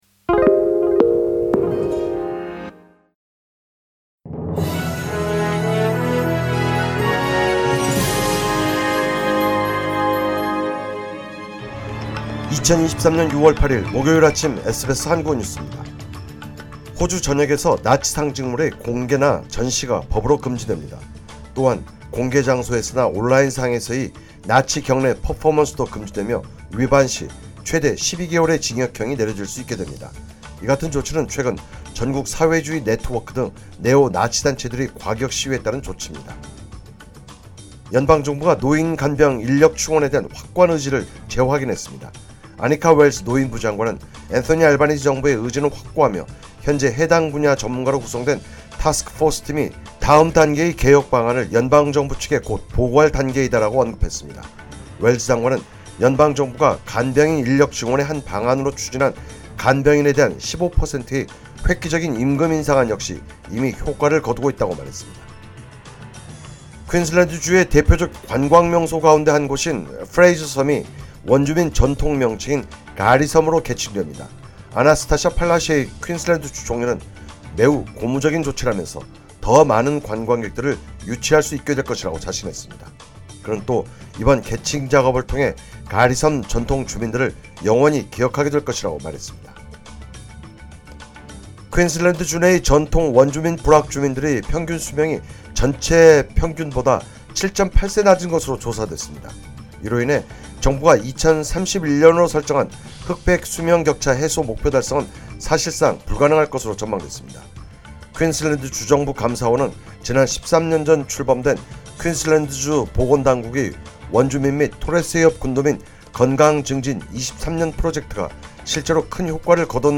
SBS 한국어 아침뉴스: 2023년 6월 8일 목요일
2023년 6월 8일 목요일 아침 SBS 한국어 뉴스입니다.